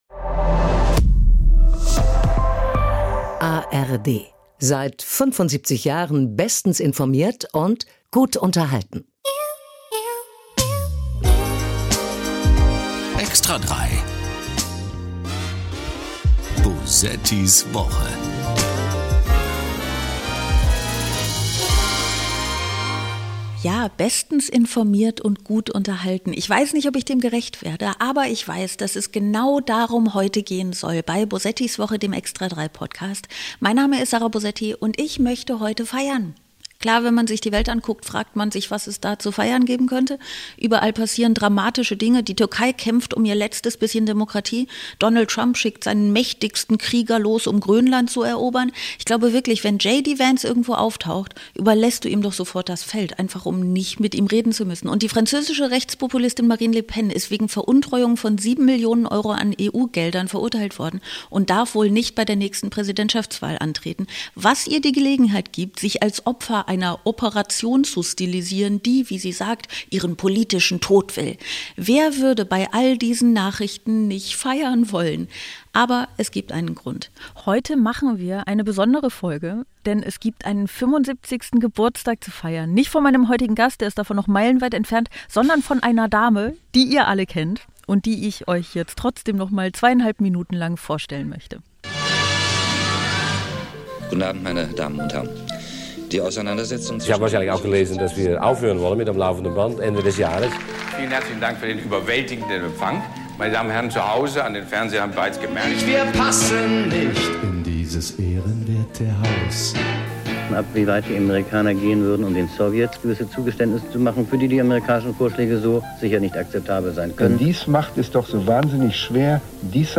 Jeden zweiten Freitag nimmt Satirikerin Sarah Bosetti die Themen der zurückliegenden Woche auseinander, die sie und ihren Gast besonders beschäftigt haben – egal ob Weltpolitik, Promi-Klatsch oder Netzempörung. Bewaffnet mit skurrilen Tönen aus Radio, Fernsehen und Internet wird rund 45 Minuten verspottet, überspitzt, gelacht und knallhart all das kommentiert, was eben schiefläuft – genau so kümmert sich „extra 3“ seit mehr als 40 Jahren im Fernsehen um den Irrsinn der Woche.